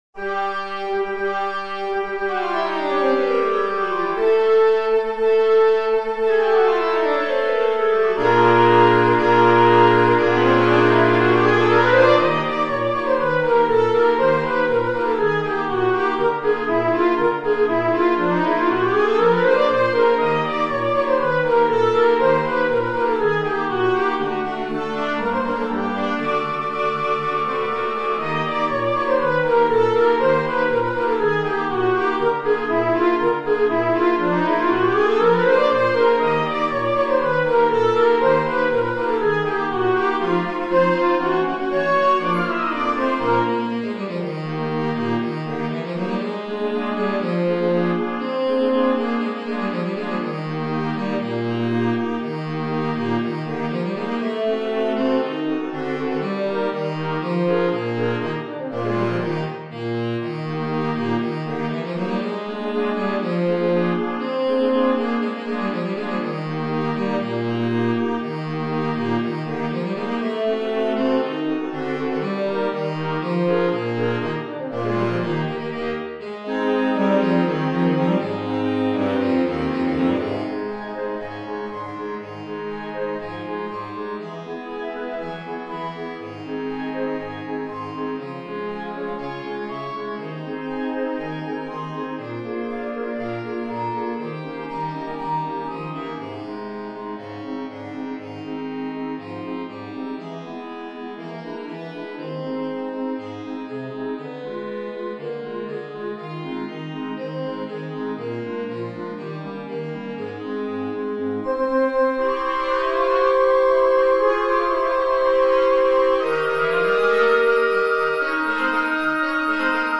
It is presented here for a wind ensemble:
Flutes
Clarinets
Alto and Tenor Saxophones
Bass Clarinet
Trumpet
Horn
Bassoon
Percussion